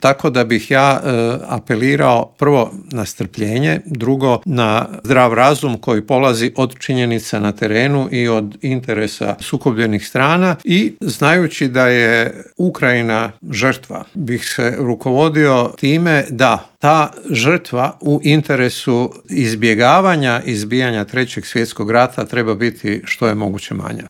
Na ta i ostala povezana pitanja u intervjuu Media servisa odgovorio je vanjskopolitički analitičar i bivši ambasador Hrvatske u Moskvi Božo Kovačević.